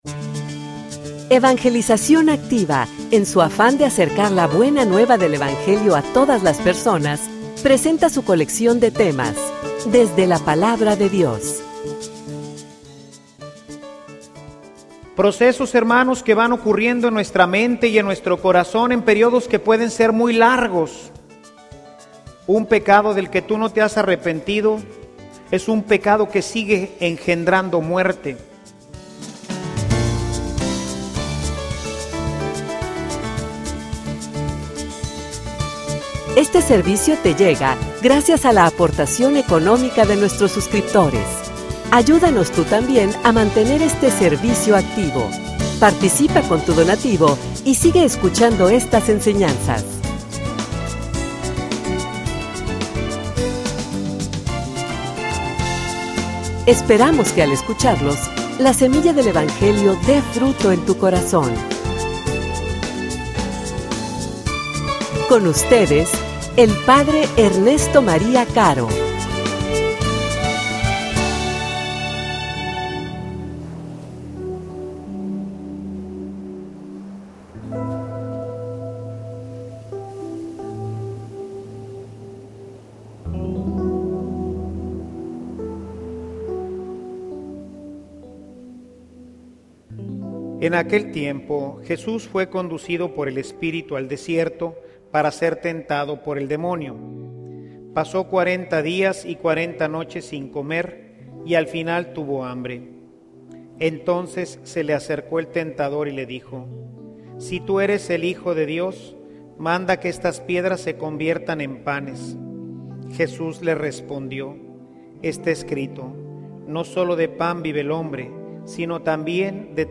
homilia_El_proceso_de_la_destruccion.mp3